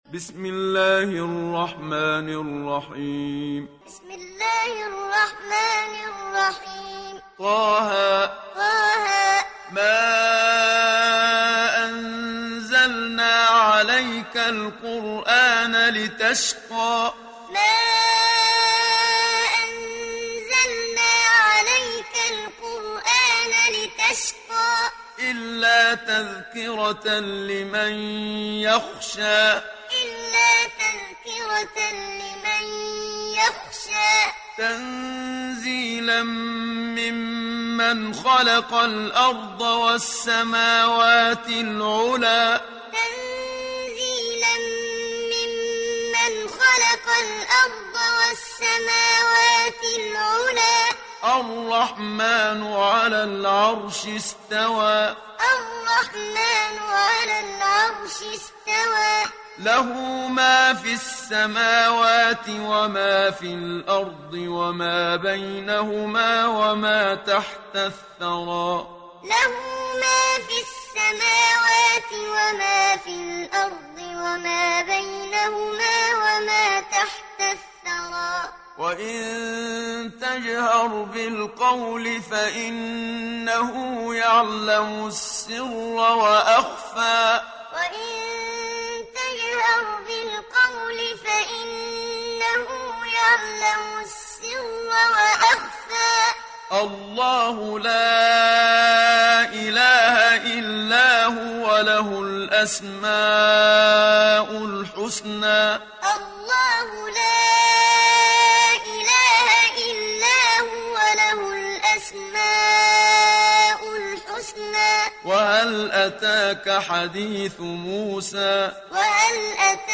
Surat Taha Download mp3 Muhammad Siddiq Minshawi Muallim Riwayat Hafs dari Asim, Download Quran dan mendengarkan mp3 tautan langsung penuh